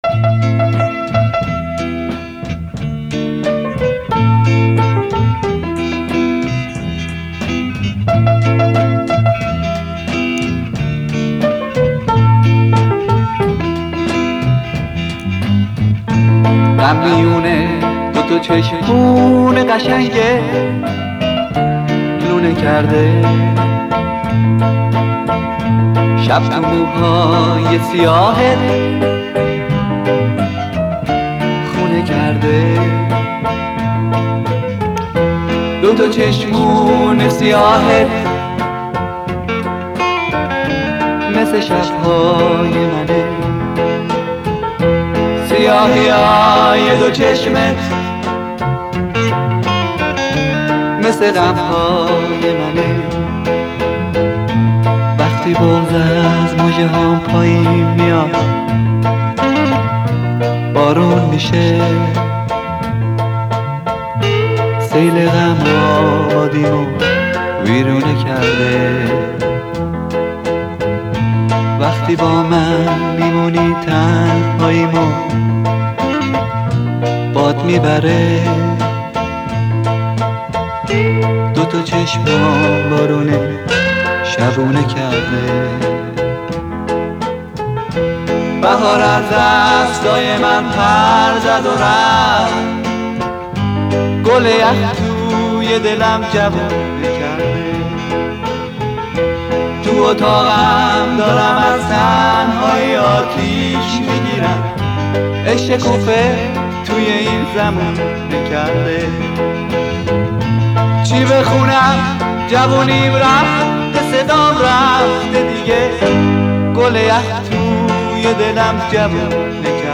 راک ایرانی